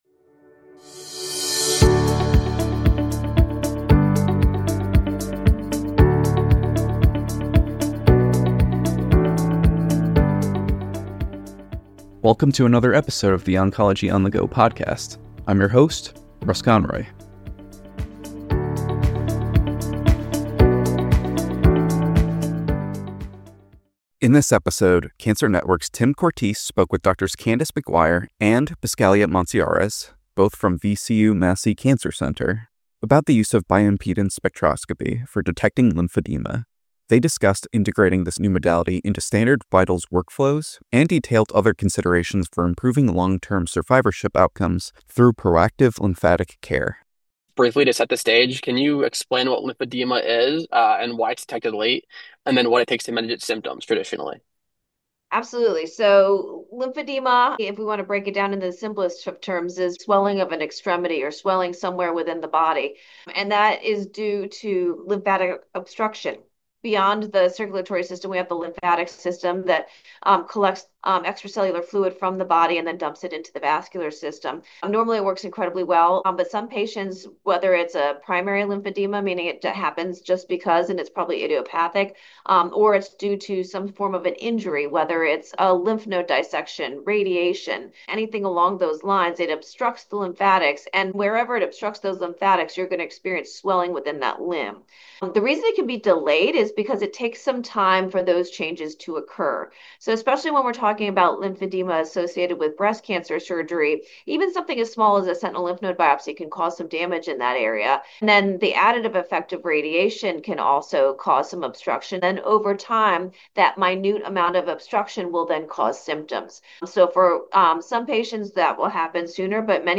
Experts from VCU Massey Cancer Center discuss seamlessly integrating bioimpedance spectroscopy into standard workflows for lymphedema detection.